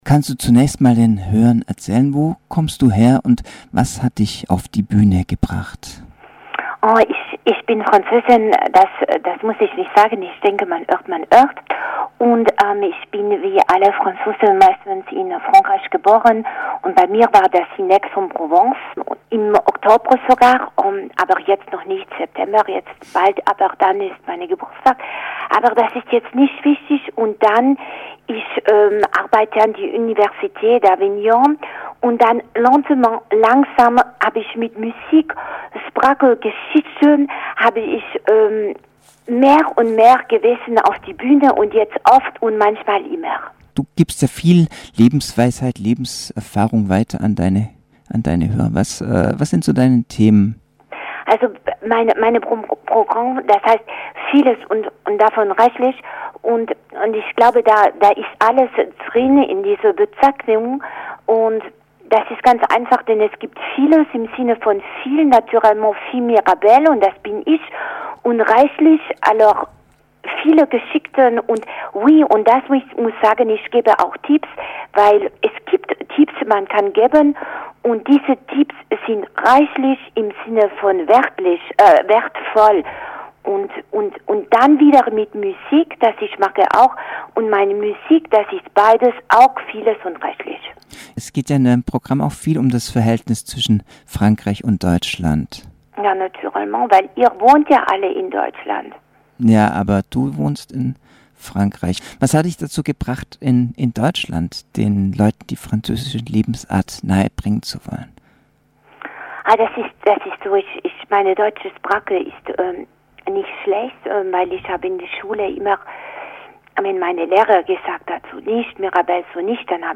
Interview Suchtpotenzial